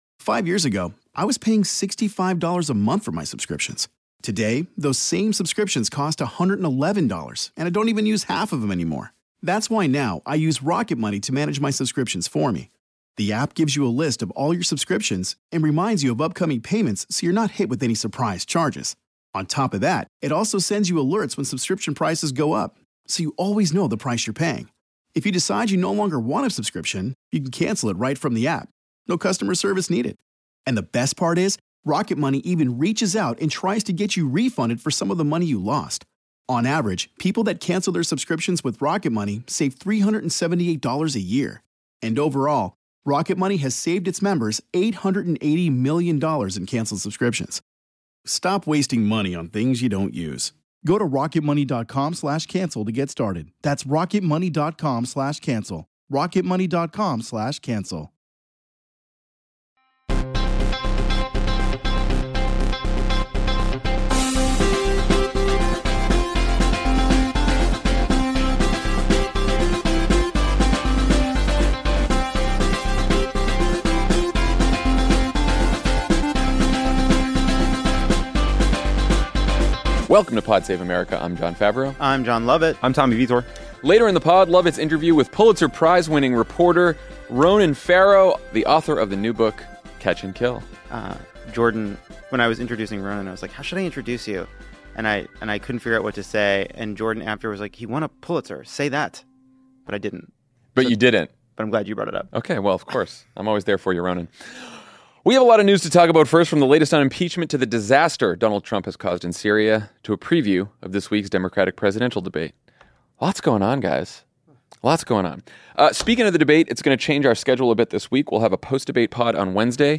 Multiple Trump officials agree to testify for the impeachment inquiry, Trump’s call with Erdogan leads to disaster in Syria, and the Democratic candidates prepare for their fourth and largest debate yet. Then Pulitzer Prize-winning journalist Ronan Farrow talks to Jon Lovett about his new book, Catch and Kill: Lies, Spies, and a Conspiracy to Protect Predators.